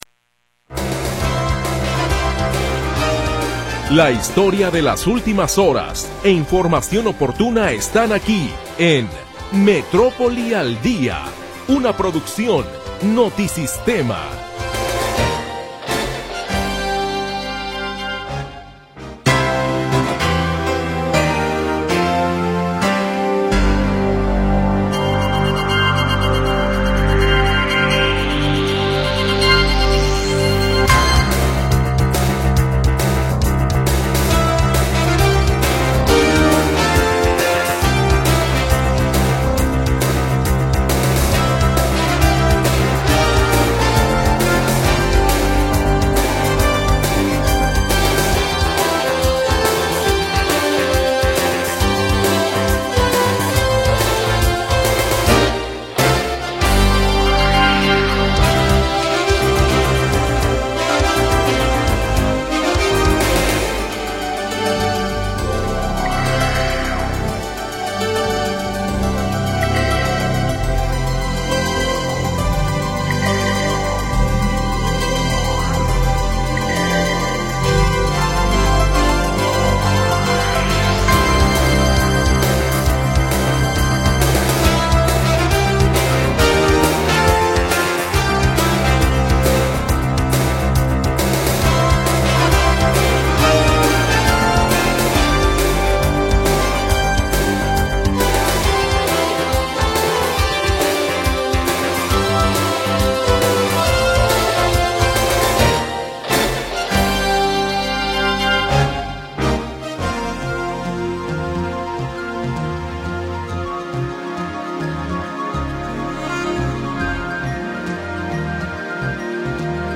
Primera hora del programa transmitido el 25 de Julio de 2025.